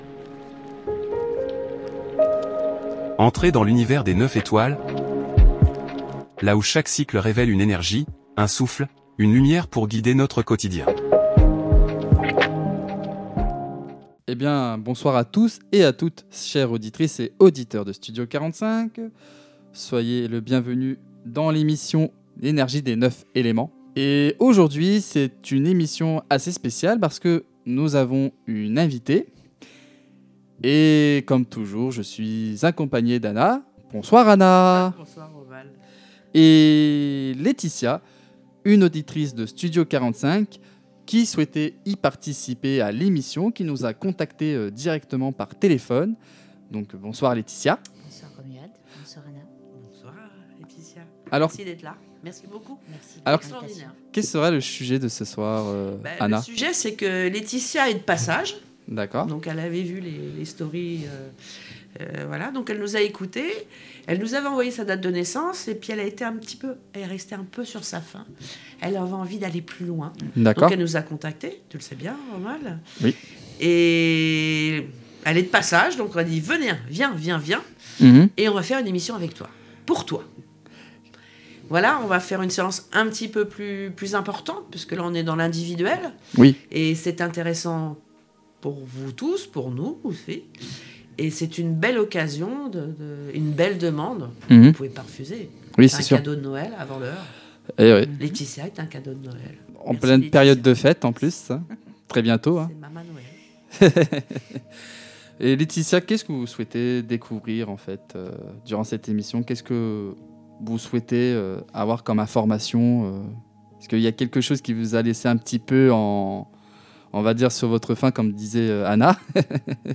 L’Énergie des 9 Étoiles – Édition spéciale avec une auditrice !
Dans cet épisode unique, L’Énergie des 9 Étoiles accueille une auditrice pour un échange spontané, chaleureux et inspirant. Au programme : ressentis, vibrations du moment, interprétations personnelles et un regard inédit sur l’énergie qui nous entoure.